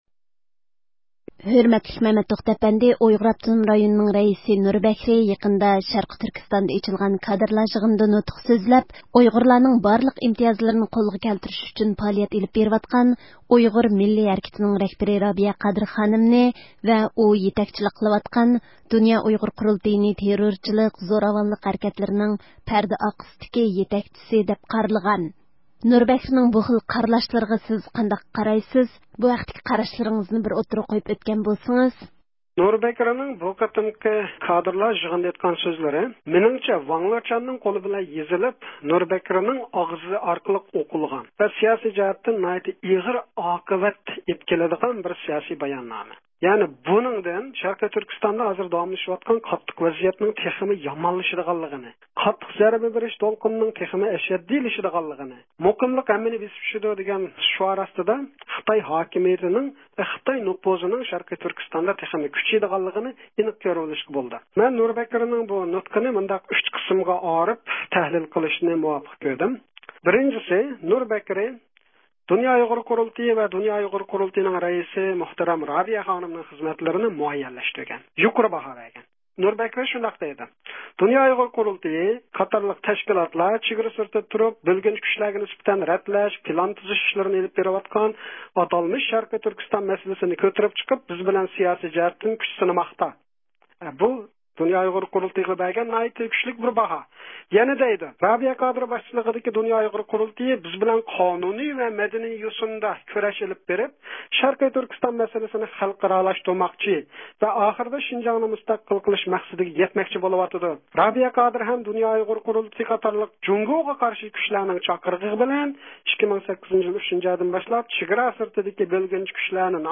سۆھبىتىنىڭ